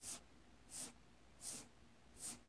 描述：有人切胡萝卜
Tag: 胡萝卜 食品 厨师